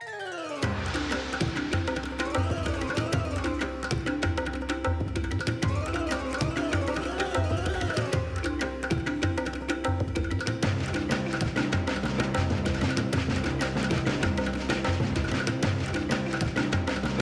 A purple streamer theme
Ripped from the game
clipped to 30 seconds and applied fade-out